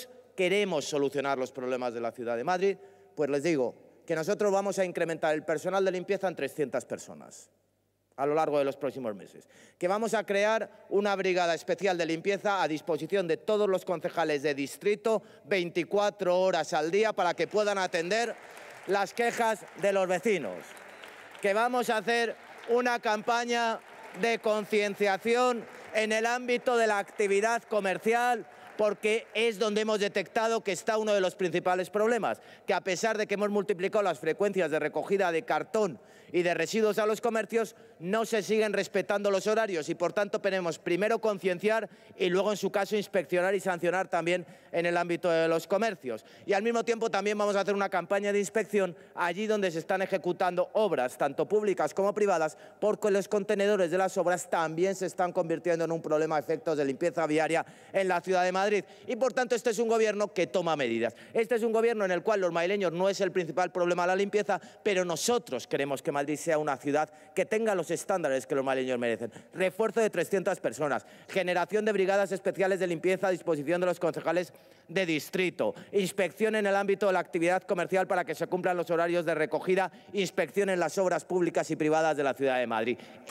Almeida da cuenta en el Pleno de la situación limpieza de Madrid y anuncia un plan con brigadas de proximidad y campañas de vigilancia
AUDIO-ALMEIDA-Plan-limpieza-Madrid-Pleno.mp3